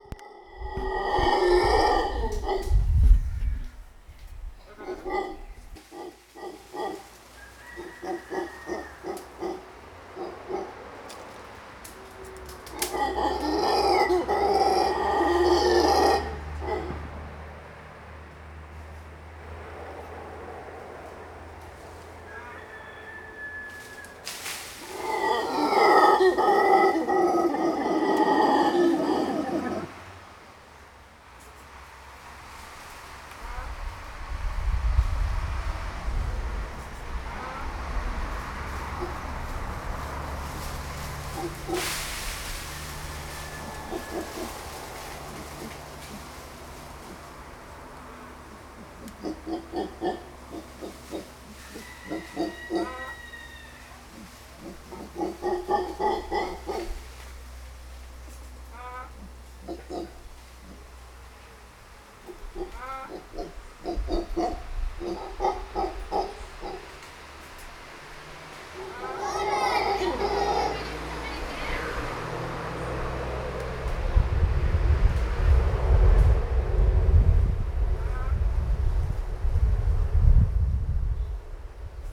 animals / monkeys